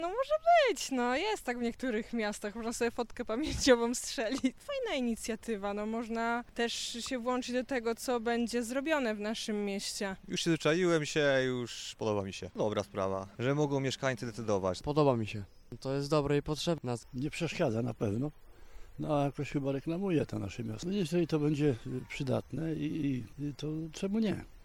Jednym z ciekawszych pomysłów jest świecący znak „Stargard” w Parku Jagiellońskim. Postanowiliśmy zapytać przechodniów, co sądzą o tym napisie oraz o samym projekcie budżetu obywatelskiego.
sonda-stargard.mp3